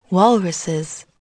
walruses.mp3